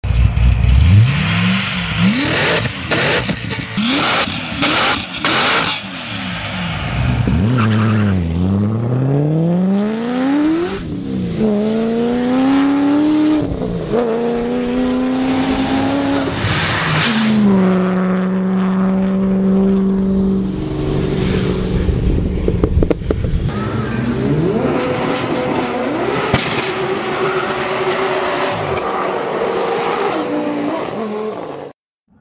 без слов
рёв движка
звук машины
Звук газующего Шевроле с лошадиной силой свыше 2000.